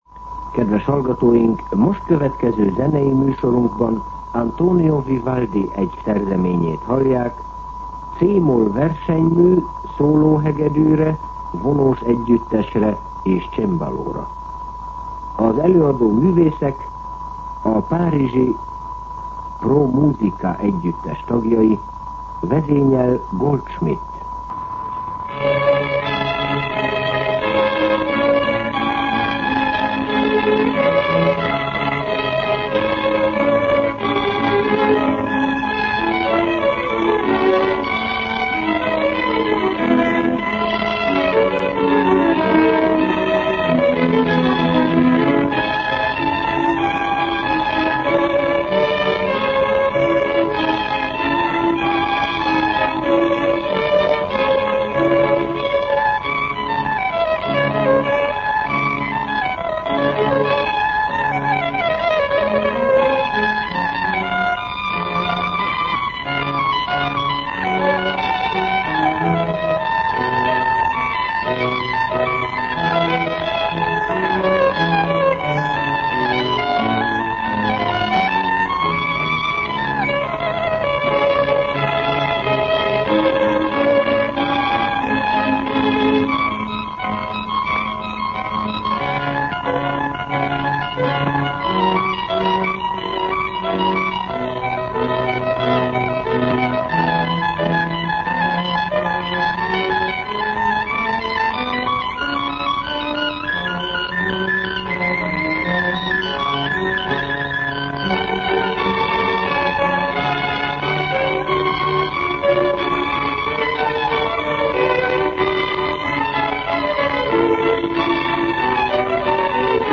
Zene